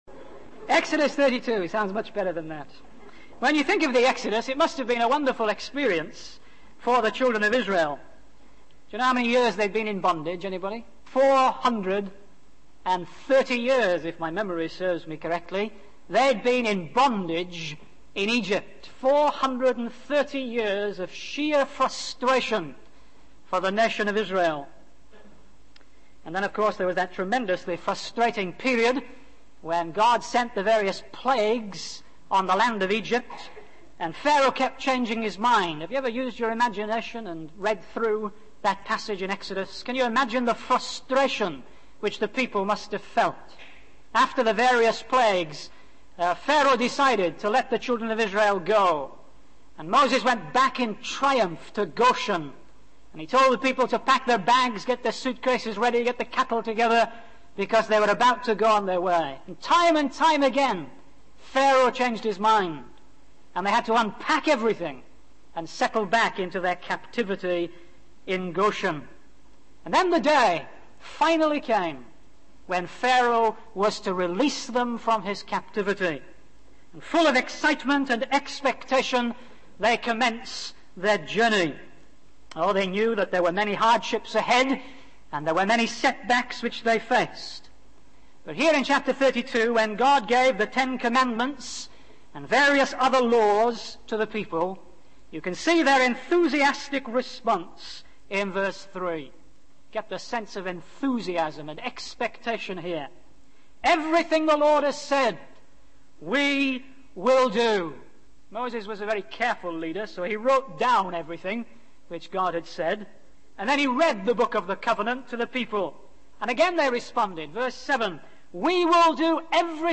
In this sermon, the preacher discusses the importance of leadership in motivating and inspiring demotivated Christians. He uses the story of Paul's shipwreck in Acts 27 to illustrate the demotivation and loss of hope that can occur in churches and Christian unions.